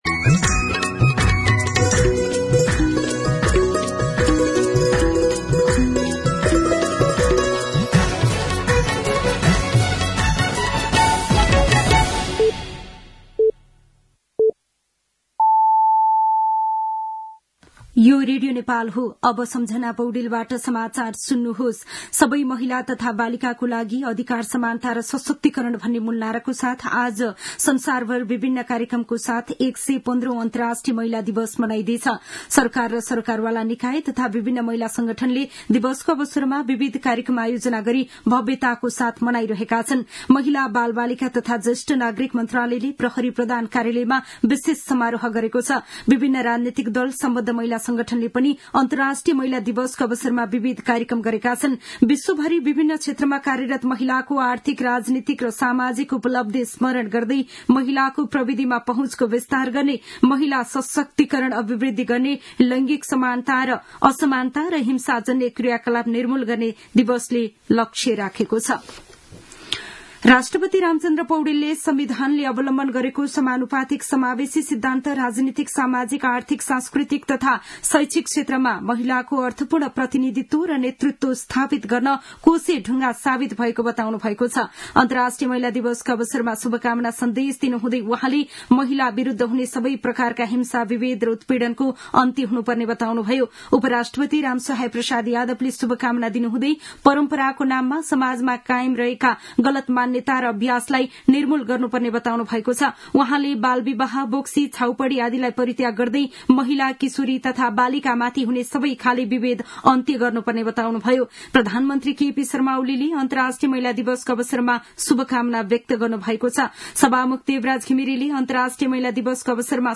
दिउँसो ४ बजेको नेपाली समाचार : २५ फागुन , २०८१
4-pm-Nepali-News.mp3